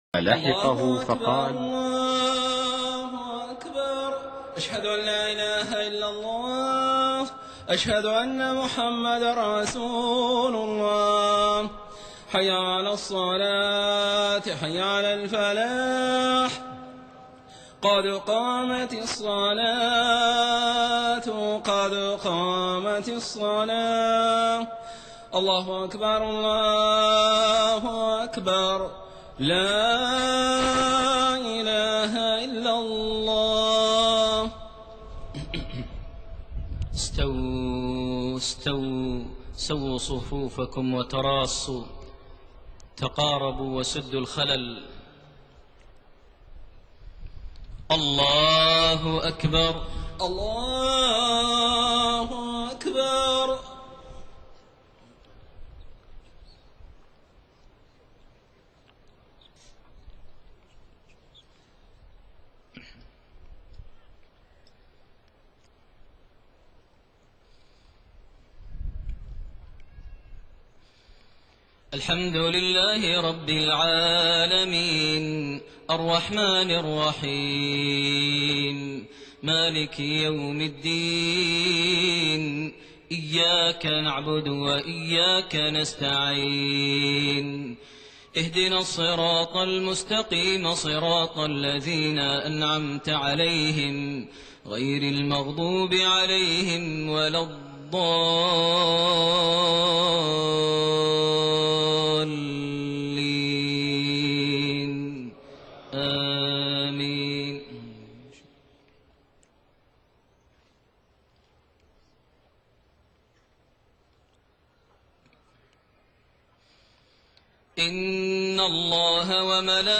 صلاة المغرب7-8-1428 من سورة الأحزاب56-62 > 1428 هـ > الفروض - تلاوات ماهر المعيقلي